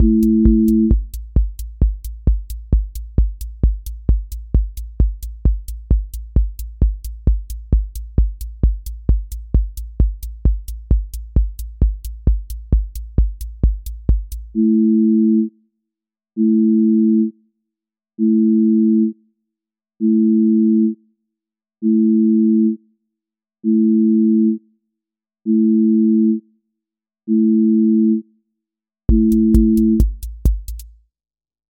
QA Listening Test trance Template: trance_euphoria
trance ascent with breakdown and drop
• voice_kick_808
• voice_hat_rimshot
• voice_sub_pulse
• fx_space_haze_light
• tone_brittle_edge